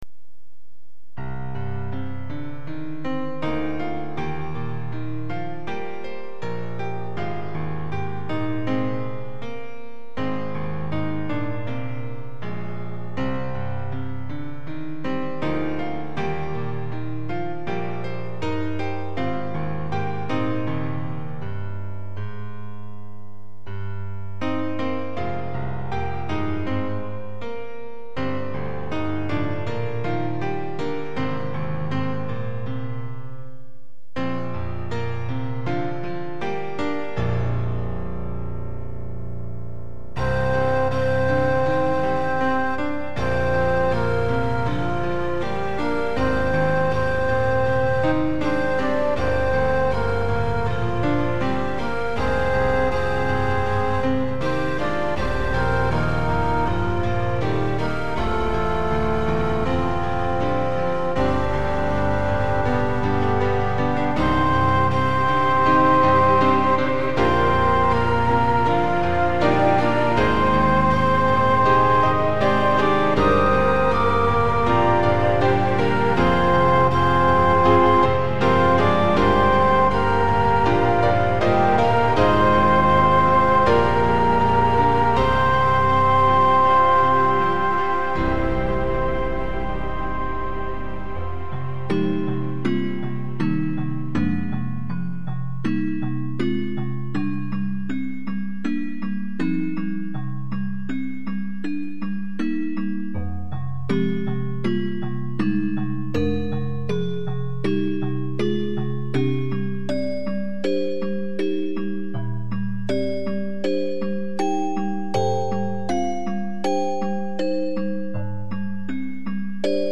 MP3は大音量推奨。